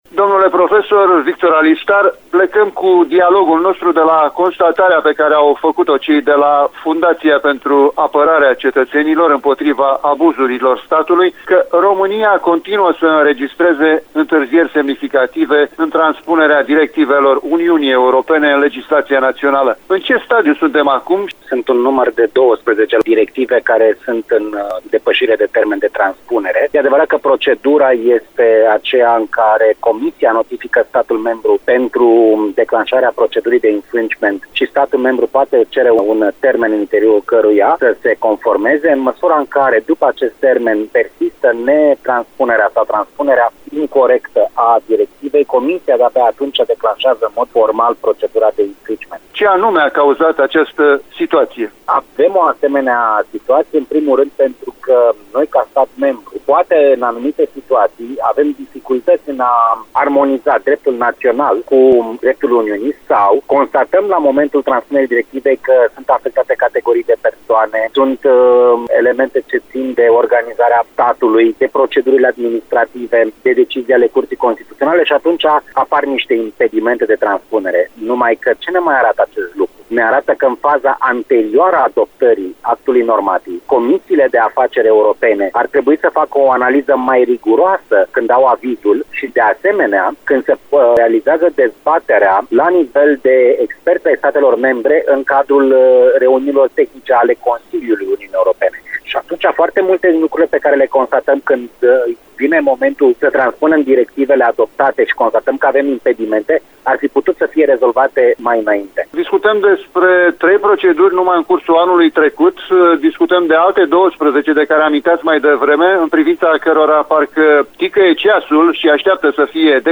a discutat despre aceste aspecte cu profesorul de drept